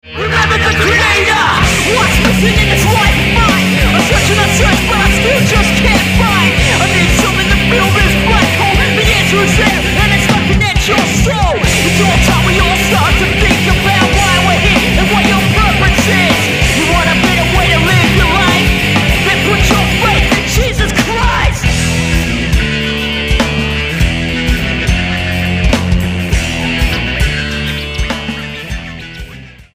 STYLE: Hard Music